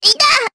Miruru-Vox_Damage_jp_02.wav